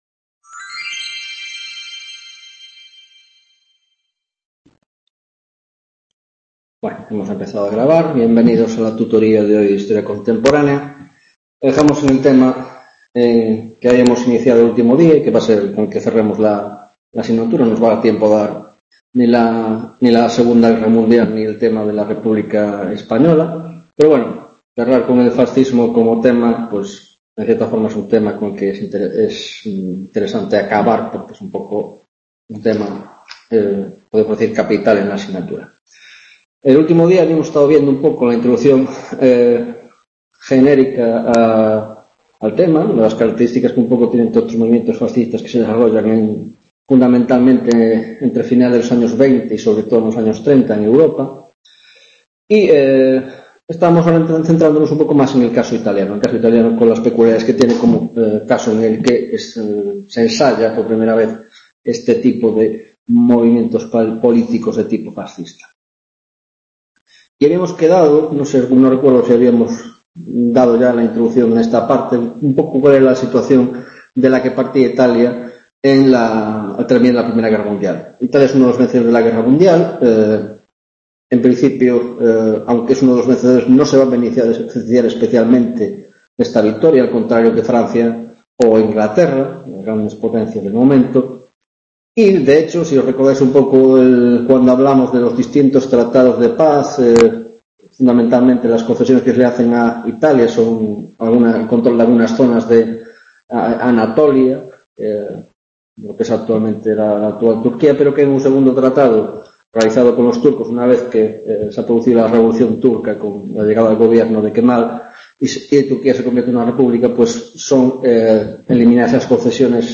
22ª tutoria de Historia Contemporánea - Movimientos Fascistas